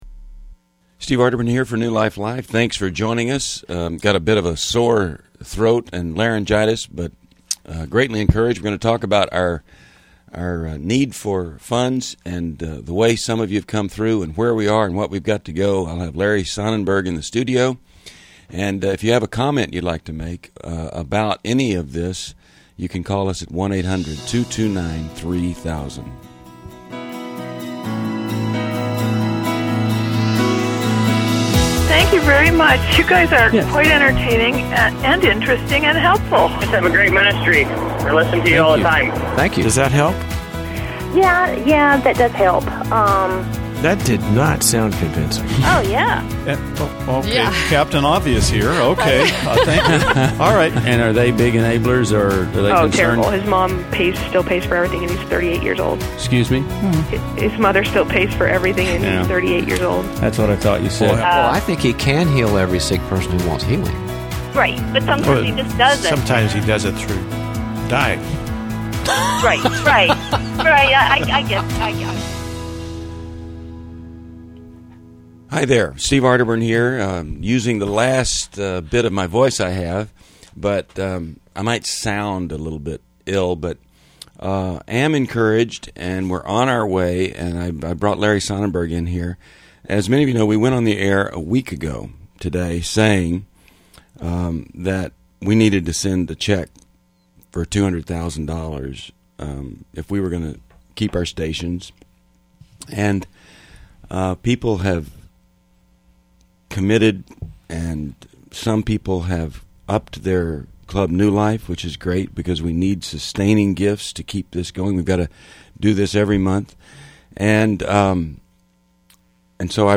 Caller Questions